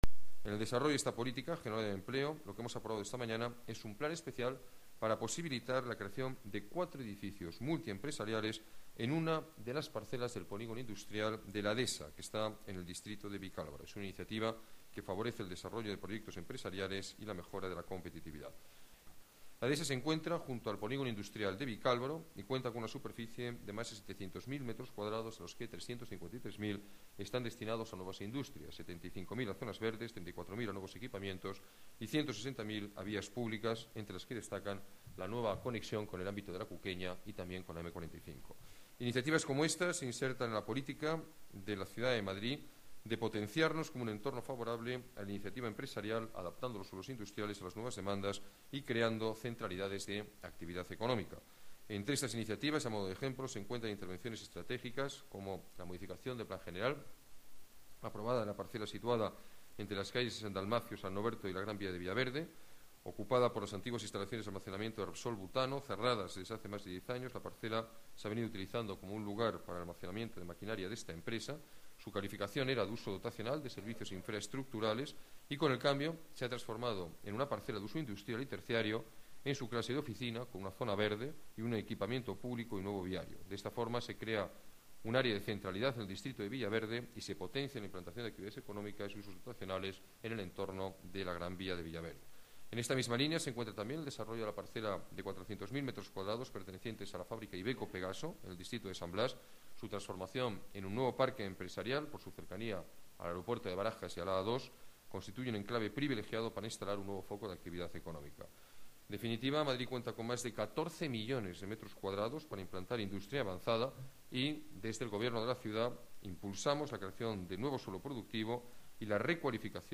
Nueva ventana:Declaraciones del alcalde, Alberto Ruiz-Gallardón